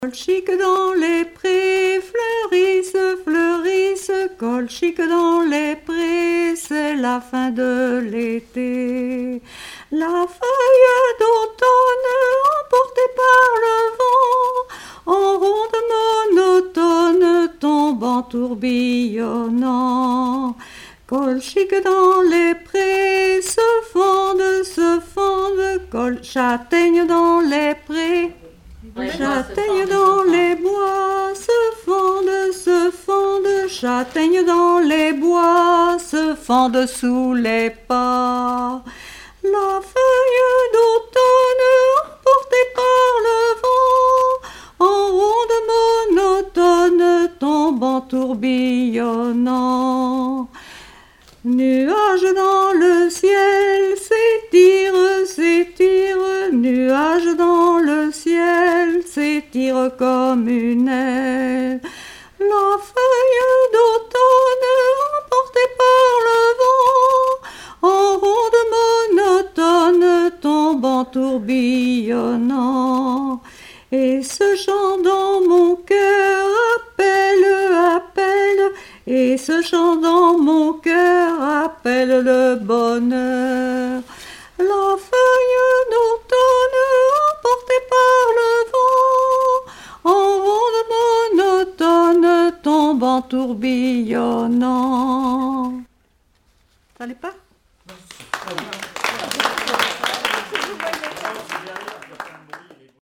Genre strophique
Collectif de chanteurs du canton - veillée (2ème prise de son)
Pièce musicale inédite